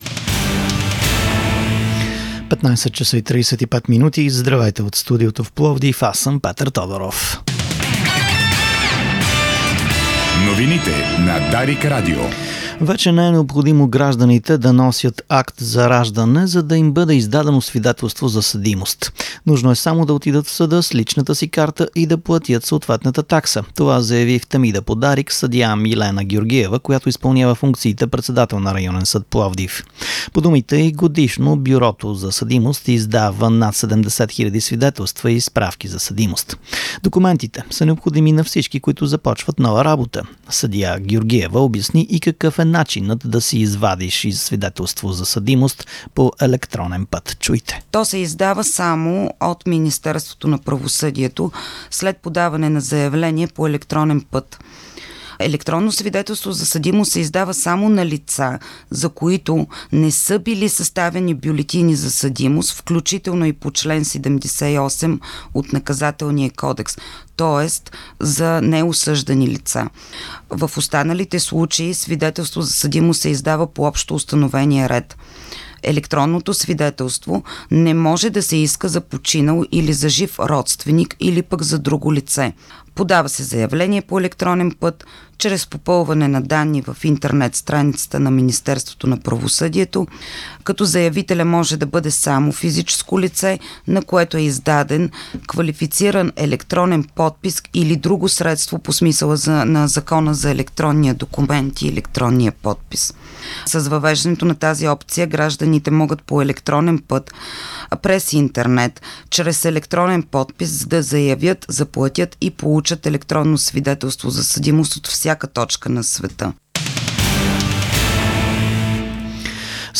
новините в 15:35 ч.